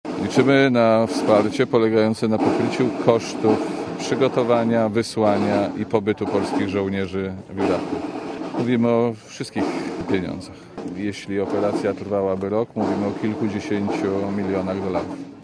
Mówi minister Jerzy Szmajdziński (120 KB)